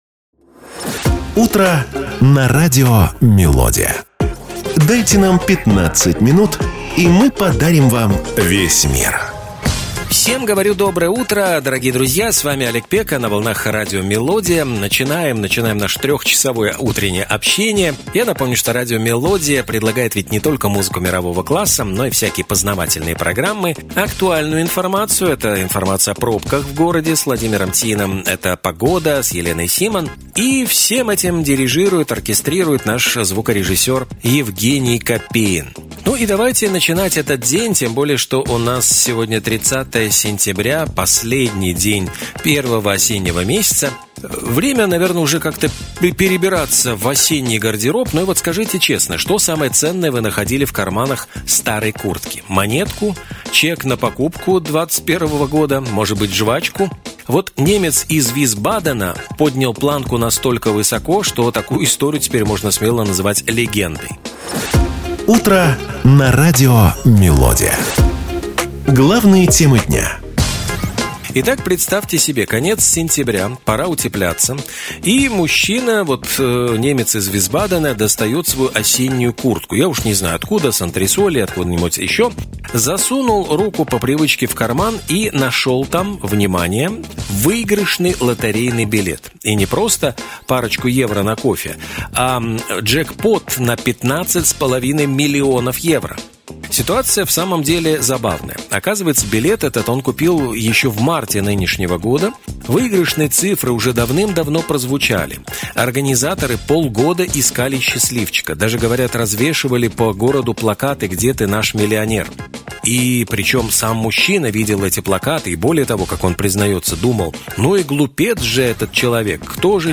Разговоры о политике, спорте, искусстве и многом другом